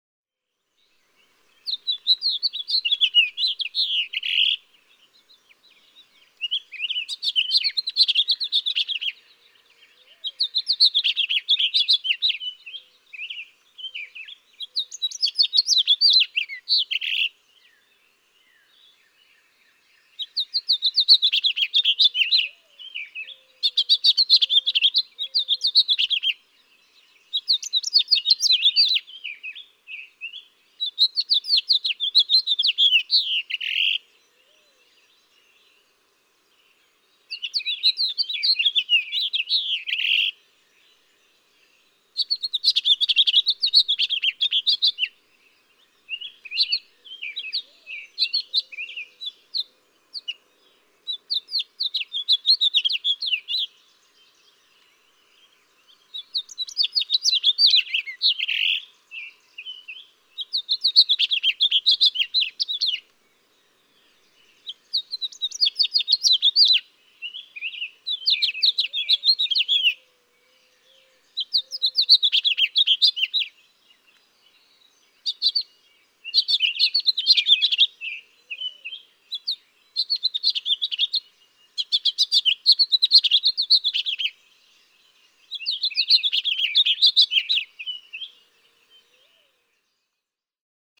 House finch
More rapid daytime song.
Vanderpool, Texas.
091_House_Finch.mp3